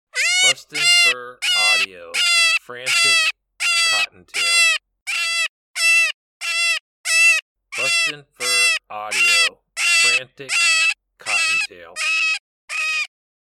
Adult Cottontail in distress.